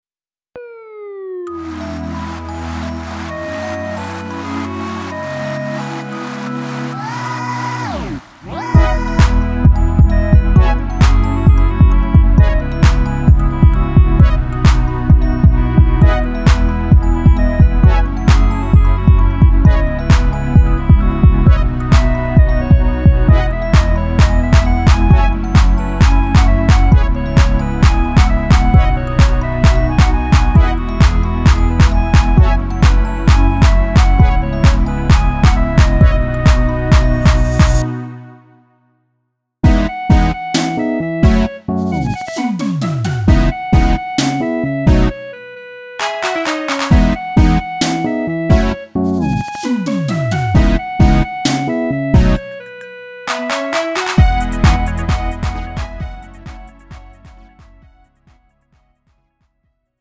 음정 -1키 2:53
장르 가요 구분 Lite MR
Lite MR은 저렴한 가격에 간단한 연습이나 취미용으로 활용할 수 있는 가벼운 반주입니다.